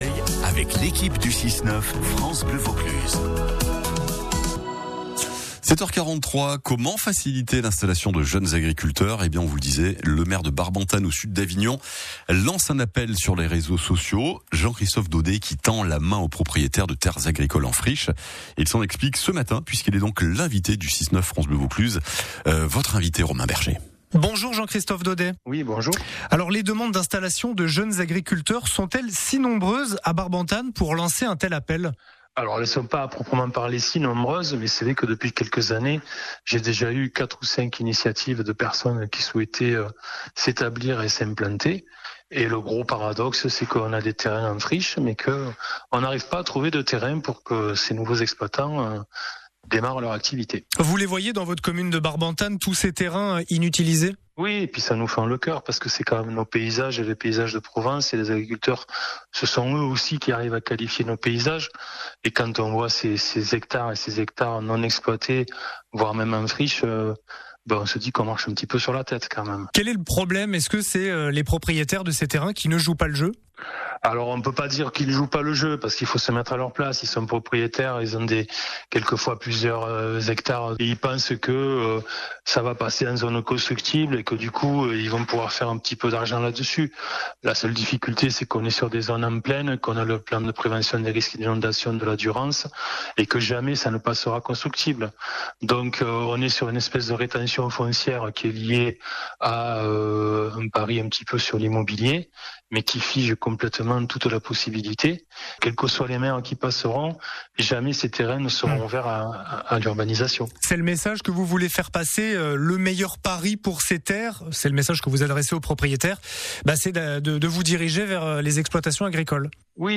Interview France Bleu Vaucluse
Interrogé récemment à la matinale de France Bleu Vaucluse, M. le Maire Jean-Christophe Daudet est revenu sur l’appel lancé sur les réseaux sociaux destiné aux propriétaires de terres agricoles en situation de friches sur la commune.